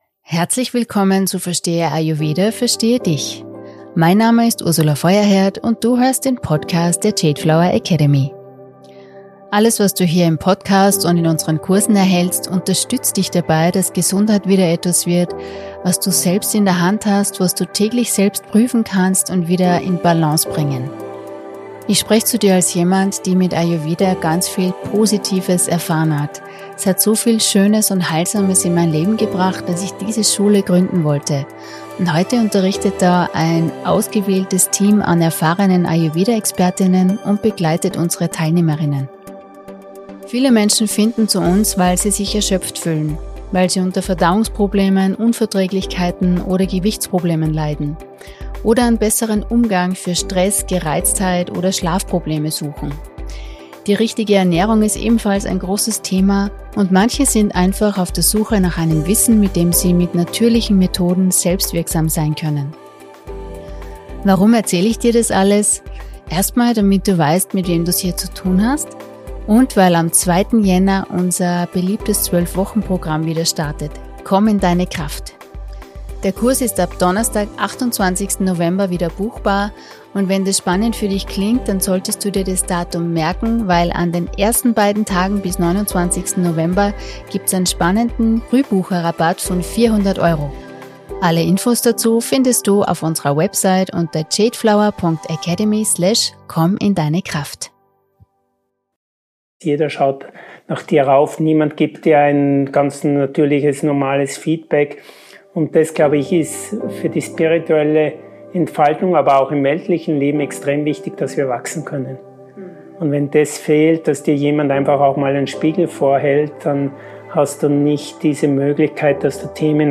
- 7 Minuten Meditation zum Mitmachen